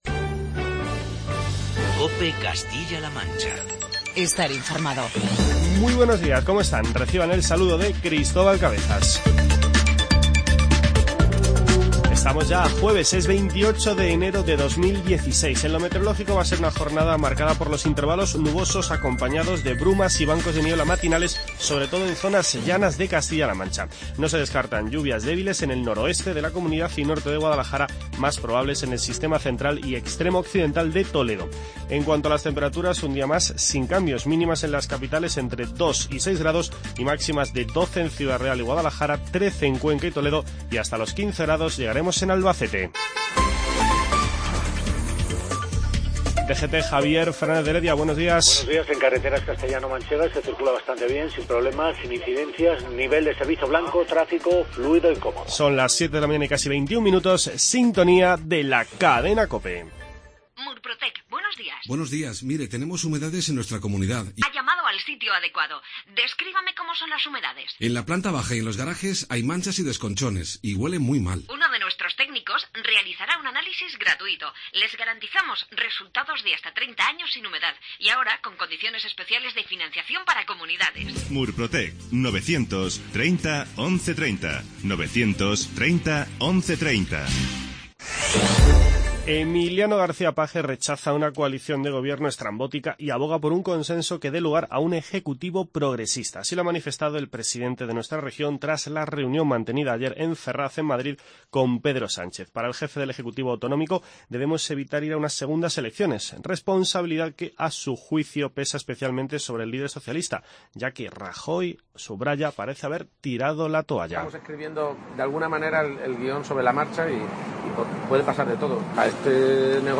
Informativo regional
Escuche las palabras de Emiliano García-Page tras la reunión que mantuvo ayer en Madrid con Pedro Sánchez.